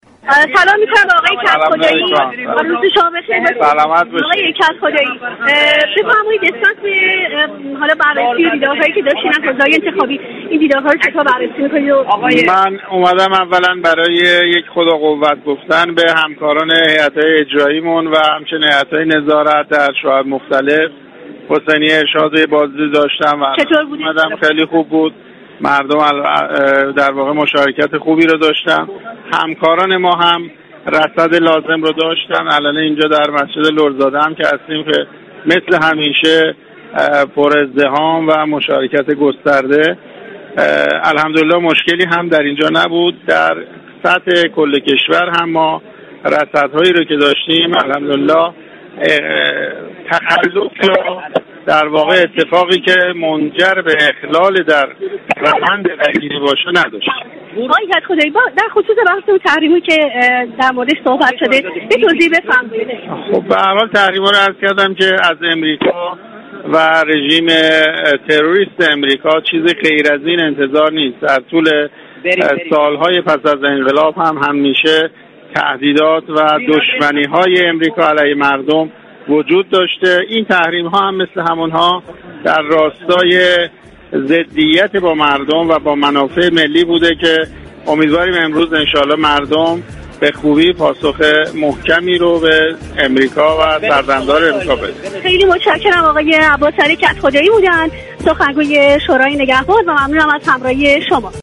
عباسعلی كدخدایی سخنگوی شورای نگهبان در كفتگو با رادیو ورزش
دریكی از حوزه های اخذ رای: میزان رای و مشاركت مردم خیلی خوب هست